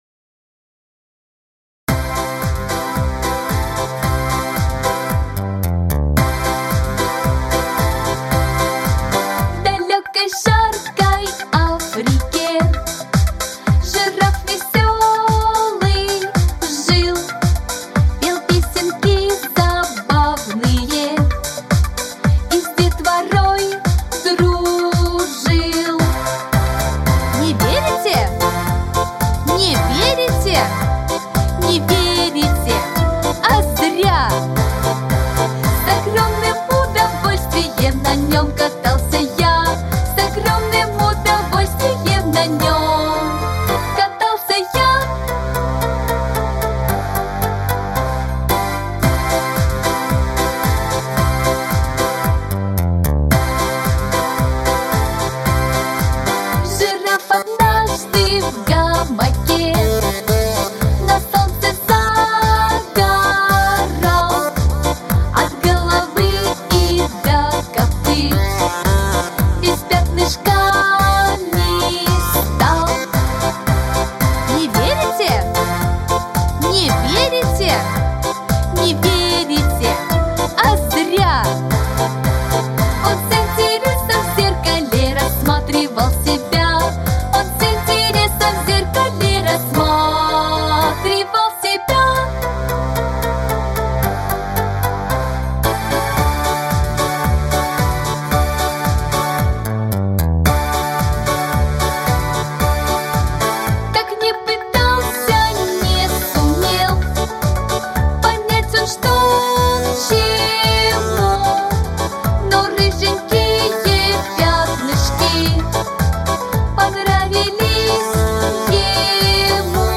Детская песня
Вокал